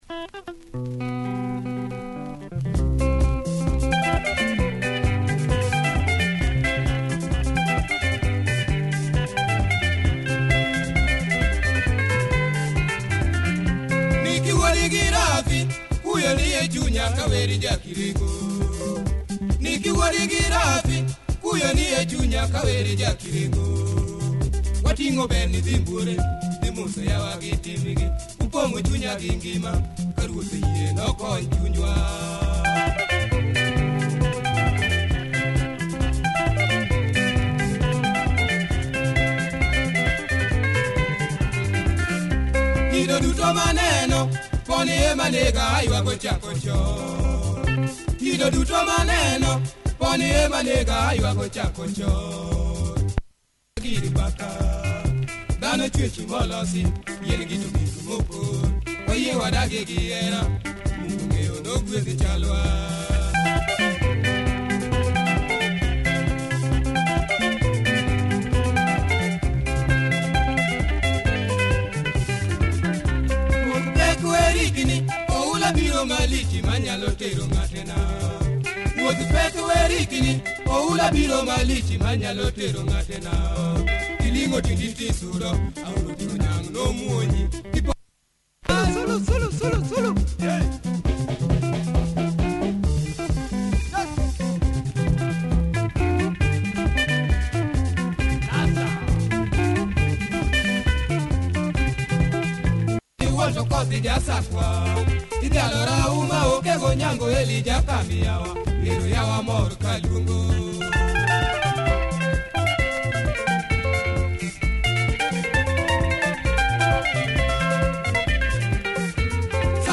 Good LUO groove, solid punch in the bass